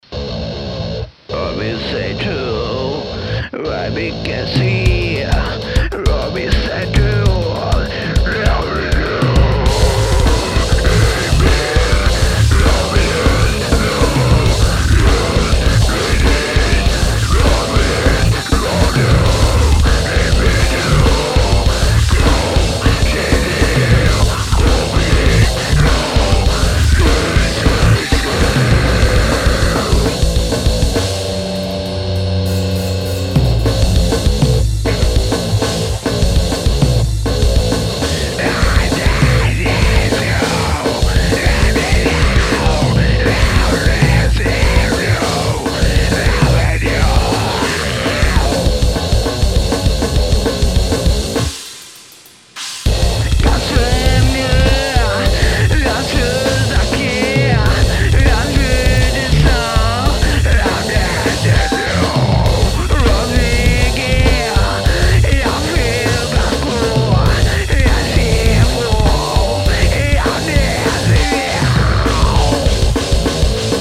Âîò ê ïðèìåðó çàïèñàë ñåíÿ íà òðåê ñâîþ ãðîóë, è ïðÿì íåìíîãî íîþùåãî ãîëîñà. (äåìêó äëÿ ãðóïïû íà èõ ìèíóñ)
ïî ìíå êàæåòñÿ âñåðàâíî òîæå íå ñêó÷íî, ýíåðãèÿ âðîäå åñòü.